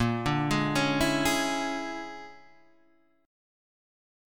A# 7th Sharp 9th Flat 5th